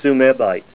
Help on Name Pronunciation: Name Pronunciation: Tsumebite + Pronunciation